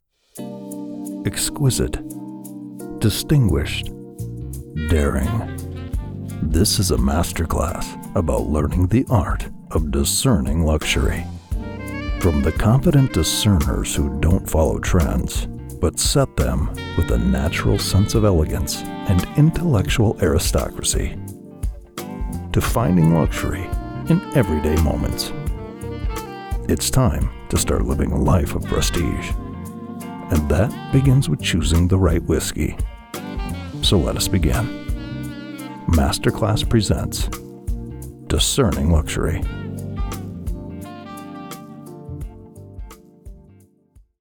My voice has been described as rich, articulate, and engaging.
Luxury Read
English - Midwestern U.S. English
I use a Sennheiser MKH 416 mic in a custom home studio.